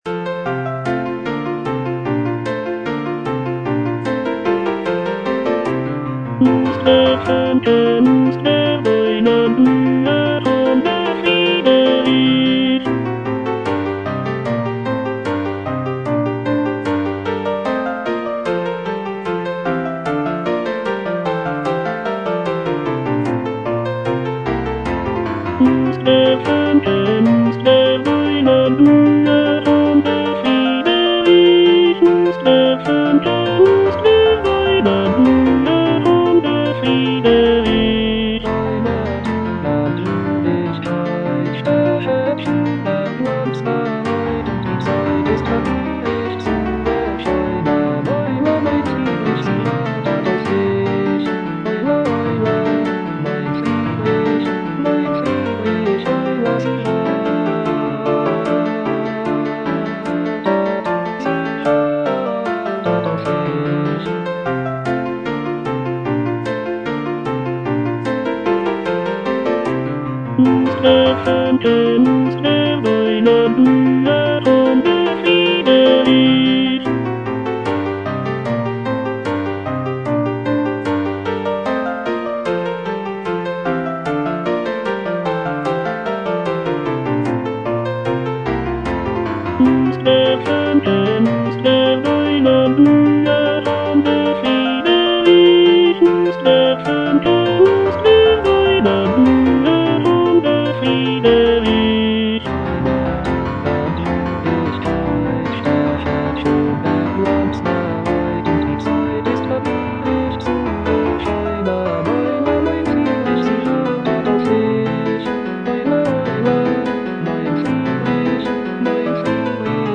Choralplayer playing Cantata
The cantata features a celebratory and joyful tone, with arias and recitatives praising the prince and his virtues.